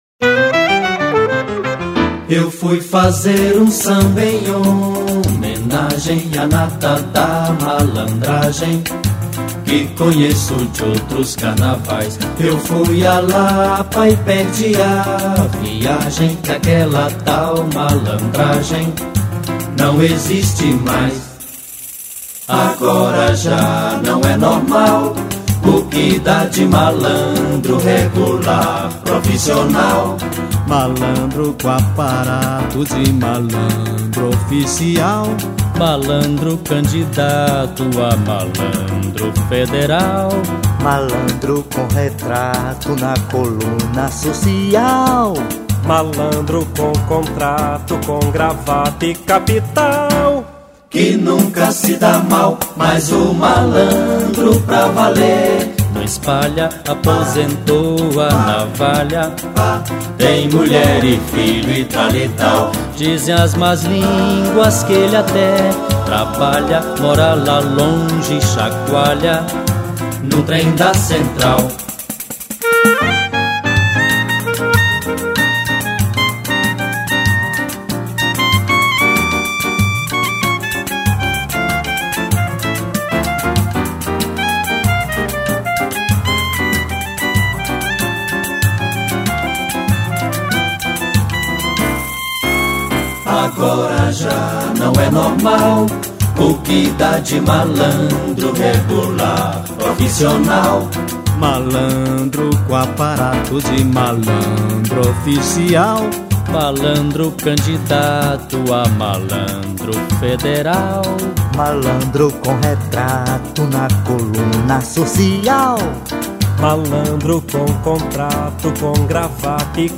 198   02:19:00   Faixa:     Samba